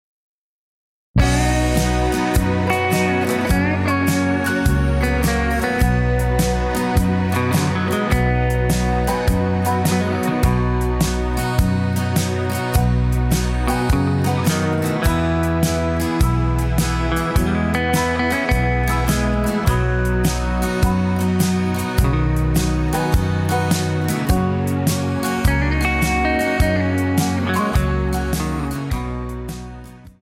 --> MP3 Demo abspielen...
Tonart:B ohne Chor